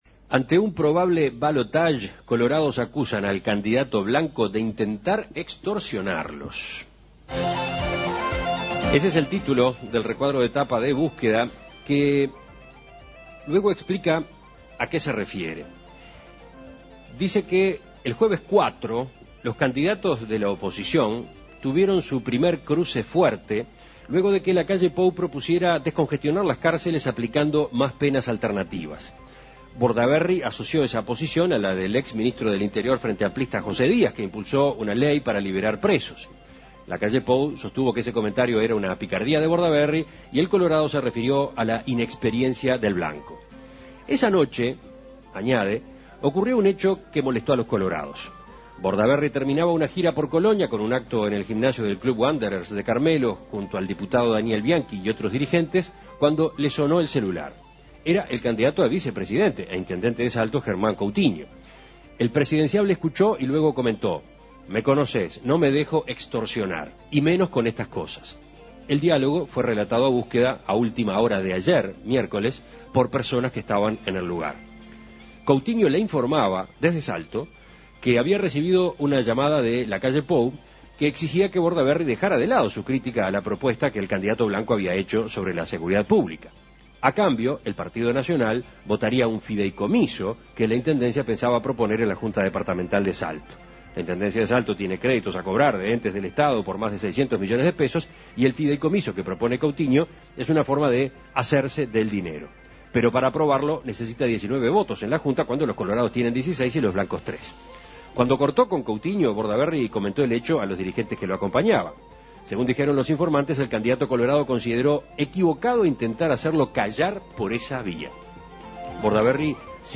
Lacalle Pou fue consultado por En Perspectiva a propósito del tema. Manifestó su molestia por lo que entiende es una grave acusación, y que temas como estos se dirimen en otros ámbitos.